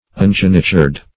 Search Result for " ungenitured" : The Collaborative International Dictionary of English v.0.48: Ungenitured \Un*gen"i*tured\, a. [Pref. un- not + geniture.]
ungenitured.mp3